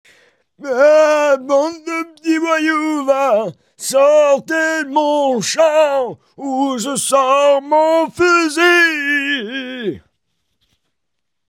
Le vieux